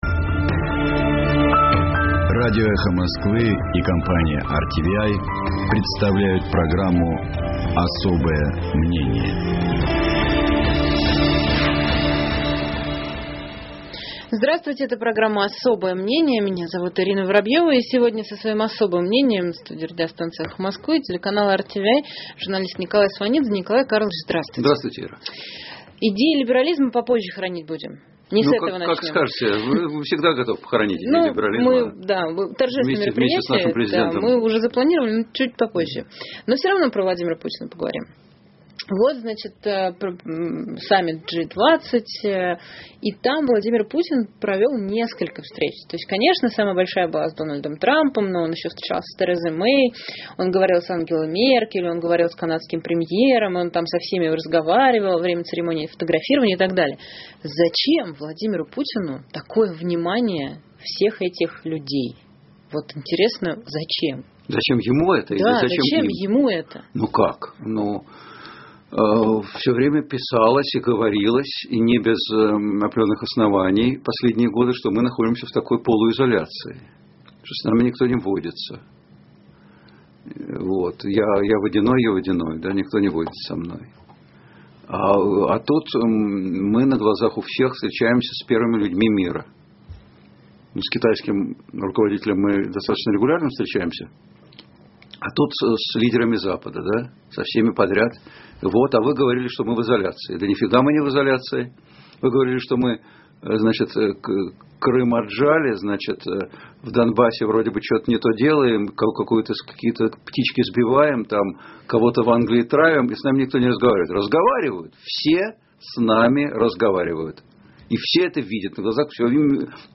И с особым мнением в студии радиостанции «Эхо Москвы», телеканала RTVi журналист Николай Сванидзе.